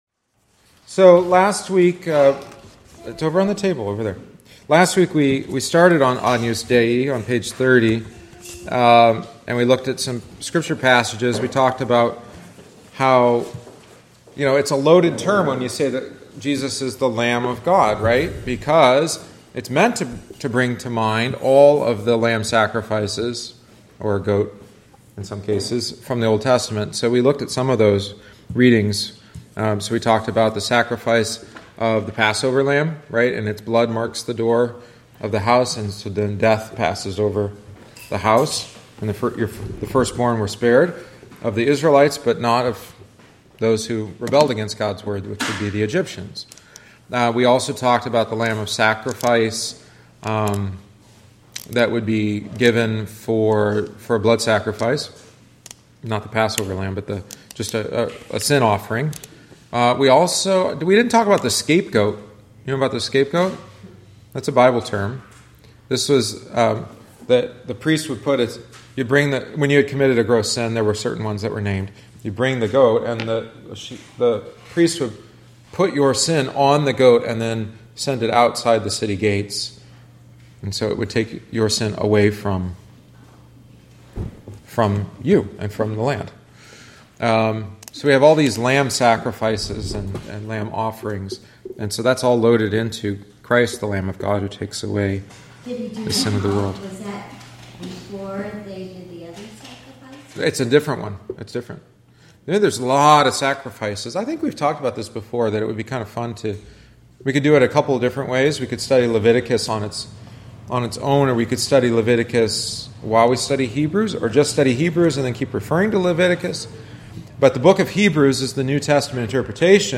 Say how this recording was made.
We discussed the famous Van Eyck Ghent altarpiece at the end of class.